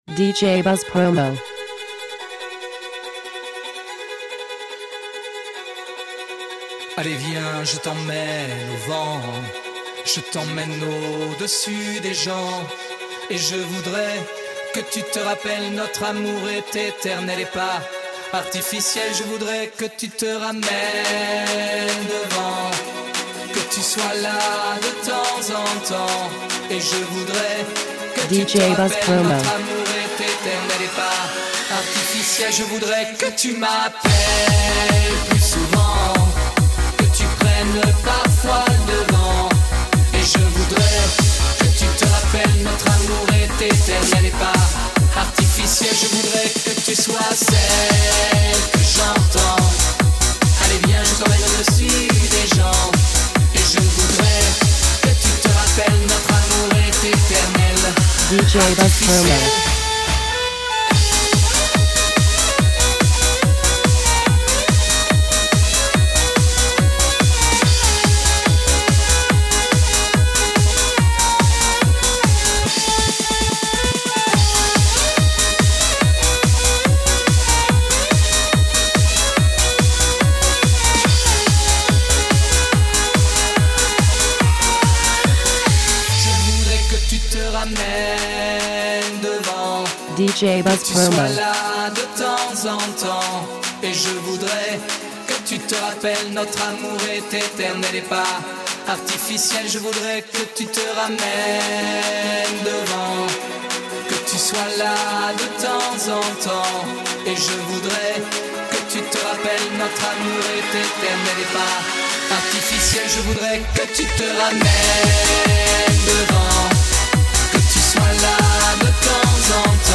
the vocals are great
In short, it’s a surefire hit for your parties!
Version Afro
Version Electro-Dance